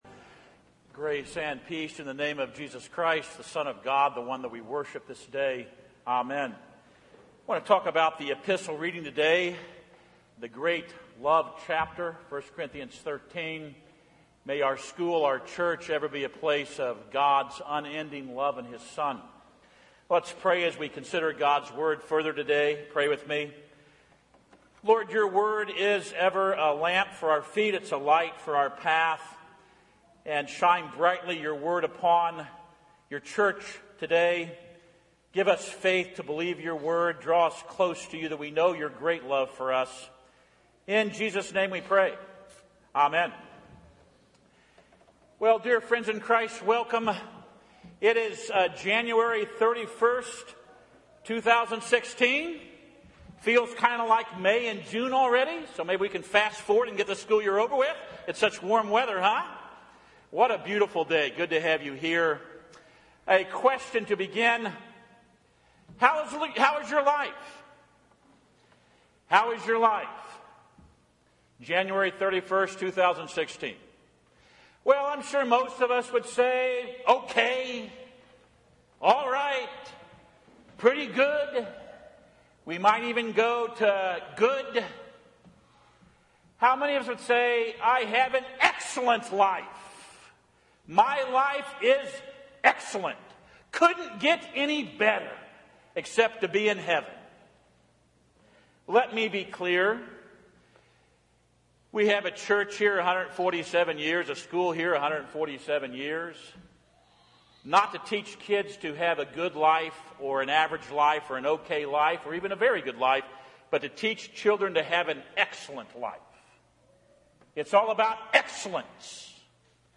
Home › Sermons › The Most Excellent Way